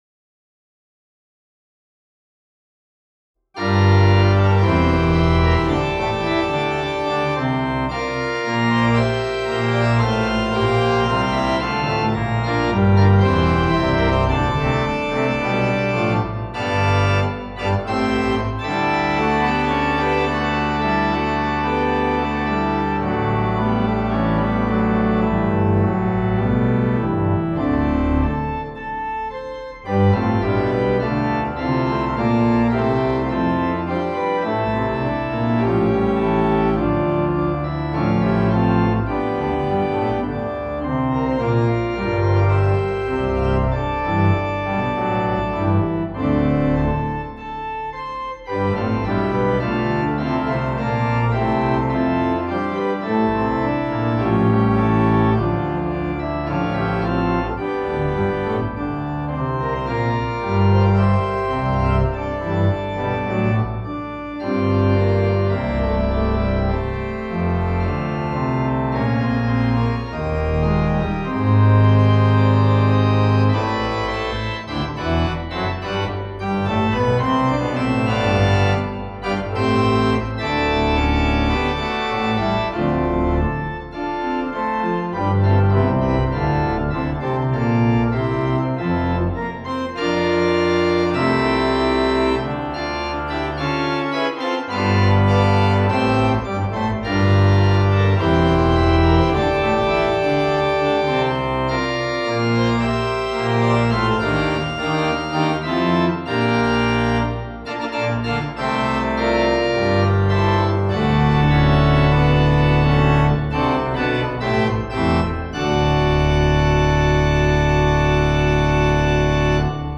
This page contains music I have created for organ (including organ and other instruments.)